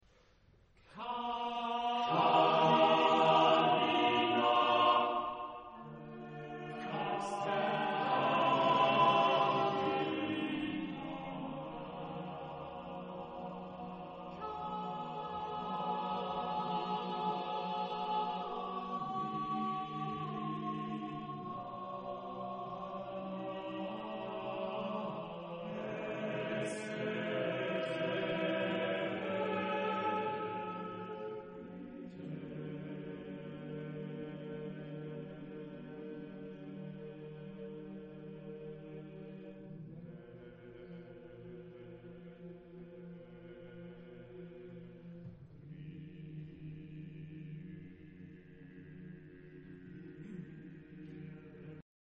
Genre-Style-Form: Sacred ; Motet ; Avant garde
Type of Choir: TTTTBBBB  (8 men voices )
Tonality: free tonality